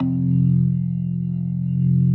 B3LESLIE F 2.wav